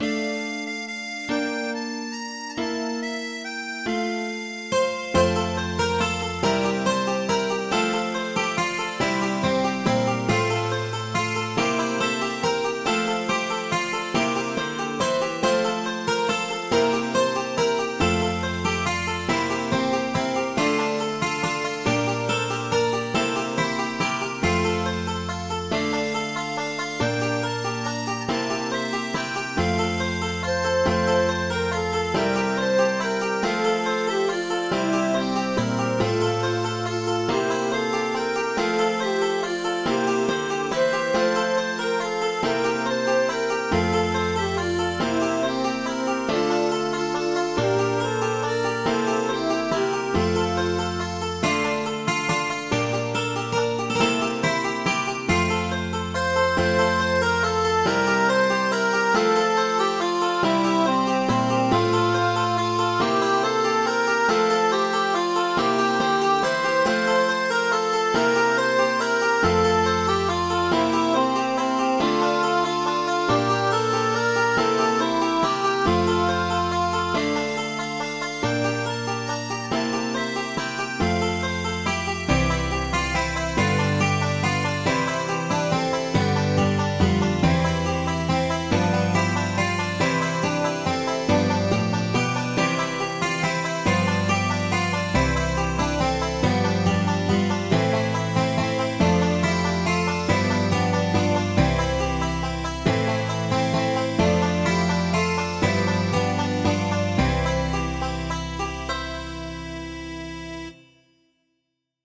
Midi File, Lyrics and Information to Streets of Laredo